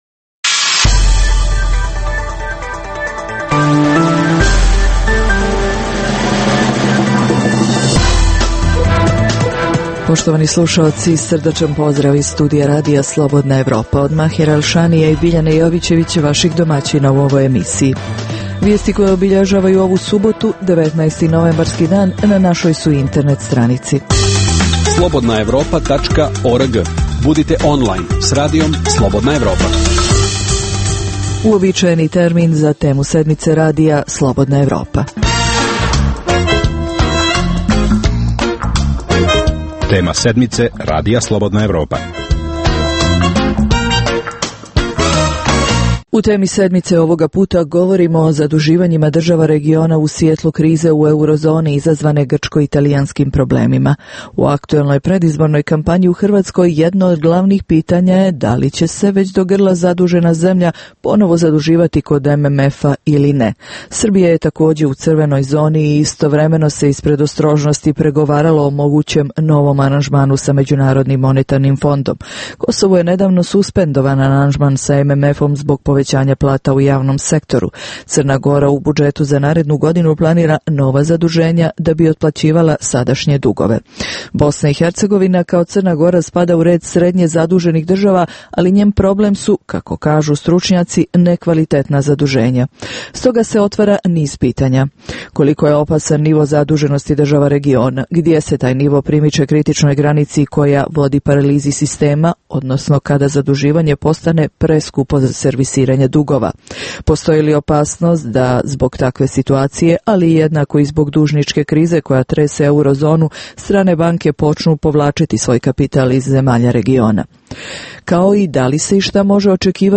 Preostalih pola sata emisije, nazvanih "Tema sedmice" sadrži analitičke teme, intervjue i priče iz života, te rubriku "Dnevnik", koji za Radio Slobodna Evropa vode poznate osobe iz regiona.